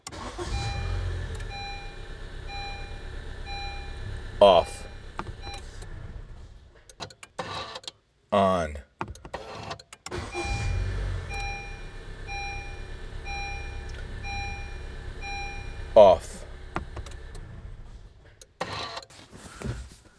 starter noise - The unofficial Honda Forum and Discussion Board Forums For Honda automobile and motorcycle enthusiasts.
I am a new owner to a 2017 honda fix It's got 35k miles on it runs great. the starter of the push button make a noise when I turn it on and off I'ld like to know if this is normal. It is not the "rattle" that many comlain about, it under the steering column . if you can shed any light on it thanks here is the sound if you can hear it. Attached Files honda sound.wav (870.0 KB, 12 views) Reply